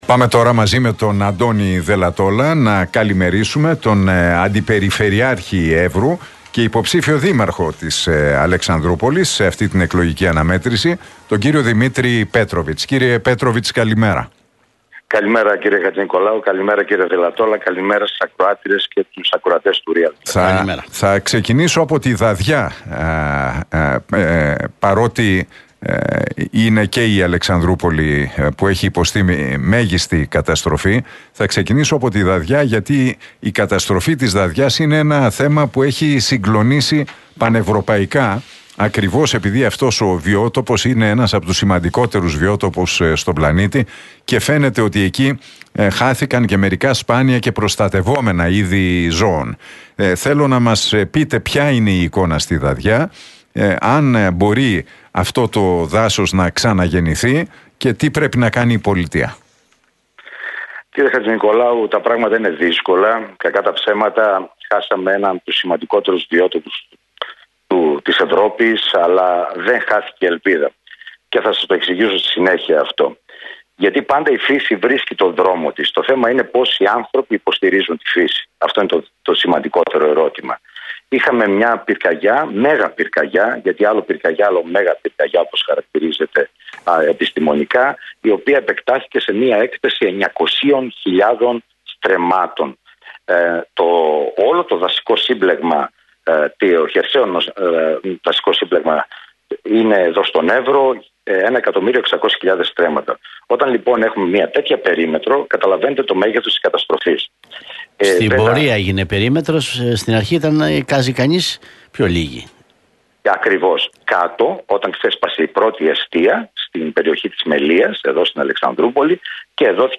Η μάχη για να αναγεννηθεί ο Έβρος μετά τις φωτιές – Τι λένε στον Realfm 97,8 o αντιπεριφερειάρχης και ο Δήμαρχος Αλεξανδρούπολης